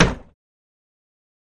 Cellar Door Close